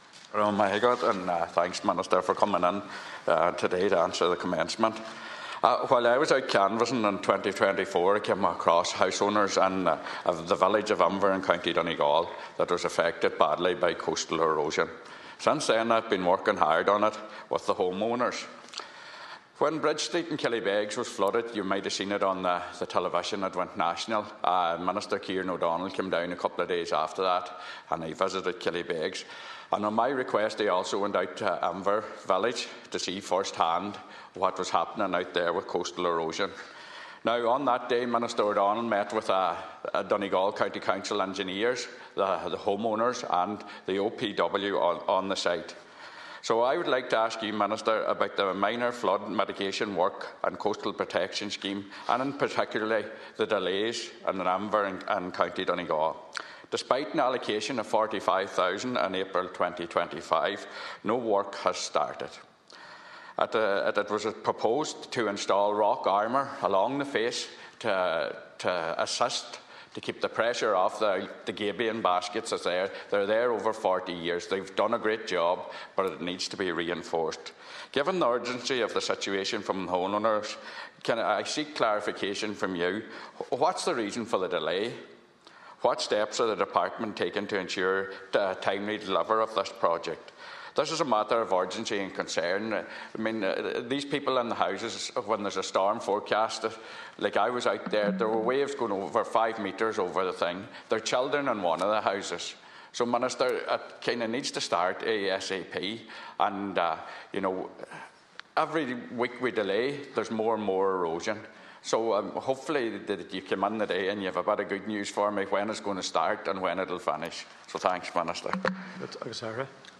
Minister of State Christopher O’Sullivan confirmed the responsibility lies with the OPW and local authorities to see the work done.
You can hear the full interaction here: